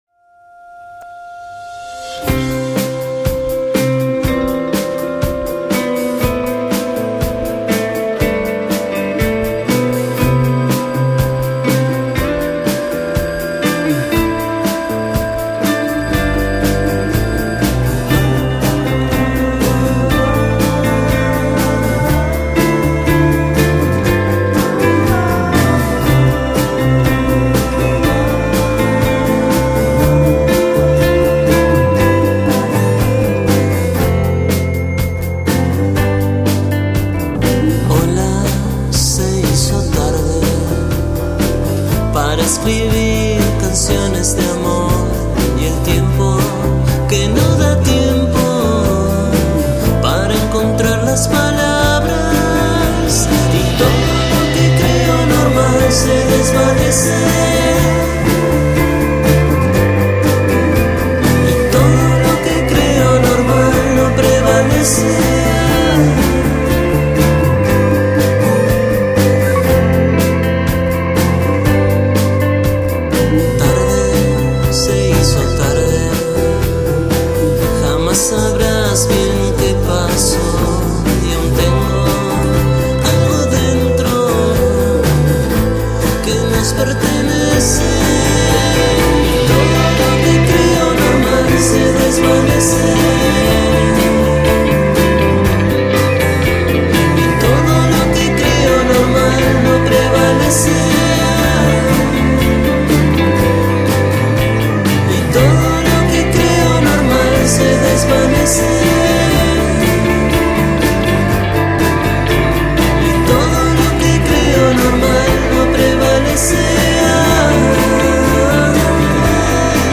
The album has important collaboration & beautiful guitars